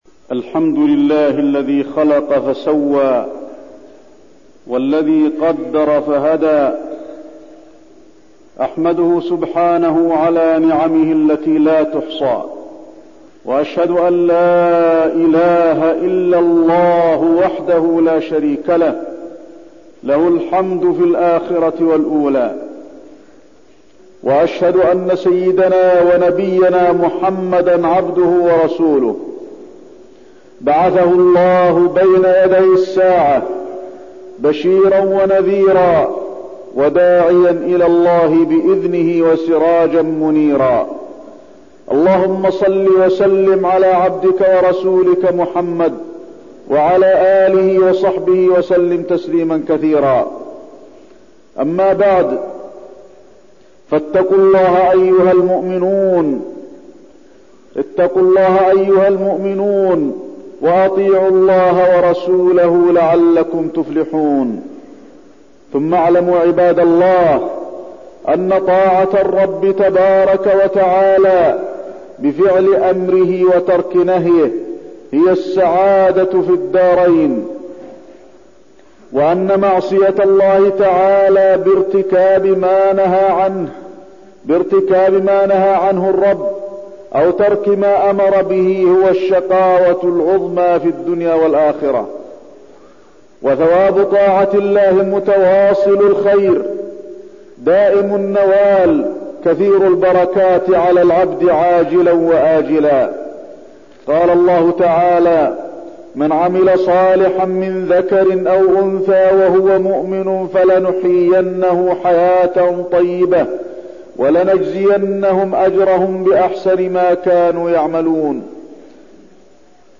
تاريخ النشر ٣ شعبان ١٤٠٩ هـ المكان: المسجد النبوي الشيخ: فضيلة الشيخ د. علي بن عبدالرحمن الحذيفي فضيلة الشيخ د. علي بن عبدالرحمن الحذيفي الحث على الطاعة